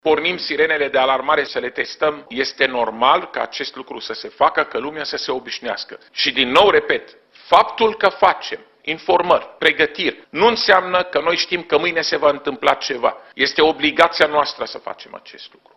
Va trebui să ne obișnuim cu acest exercițiu, deoarece așa sunt descoperite eventuale defecțiuni și, în plus, e bine să fim pregătiți pentru orice situație, spune șeful Departamentului pentru Situații de Urgență, Raed Arafat.